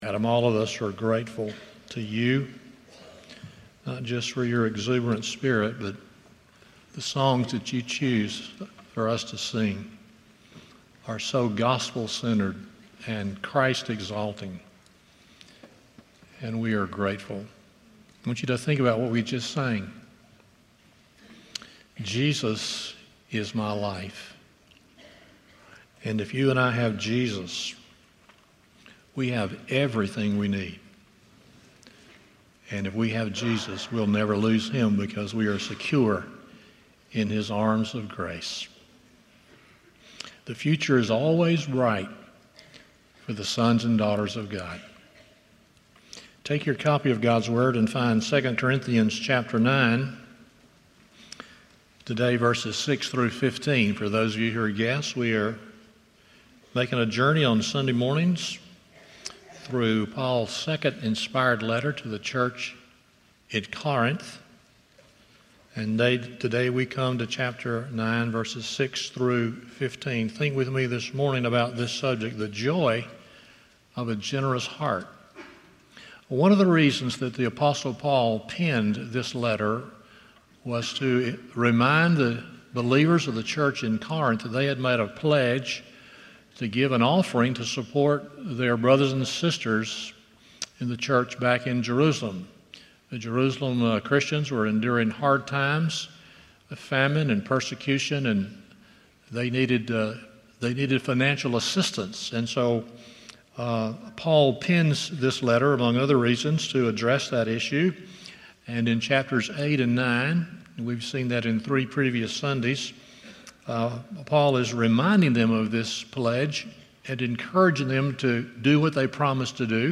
TESTIMONIES: Several Lakeview Families
2 Corinthians 9:6-15 Service Type: Sunday Morning 1.